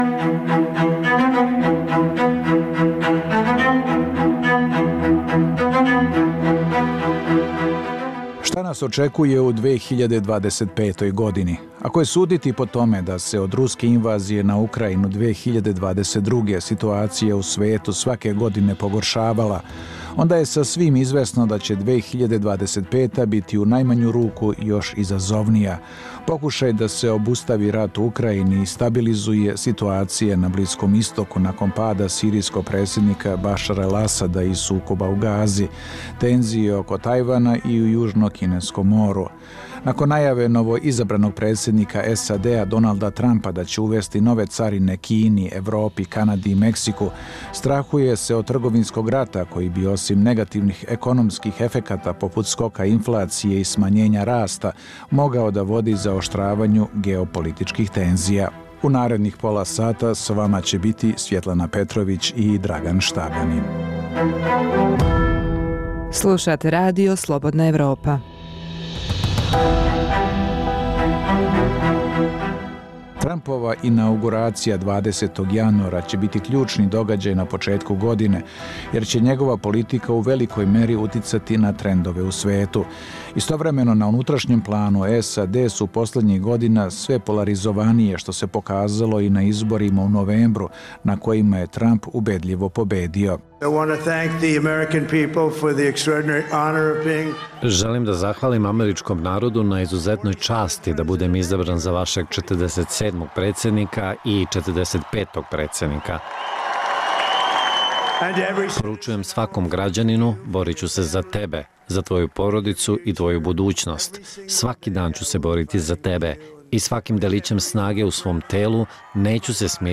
Dijaloška emisija o politici, ekonomiji i kulturi.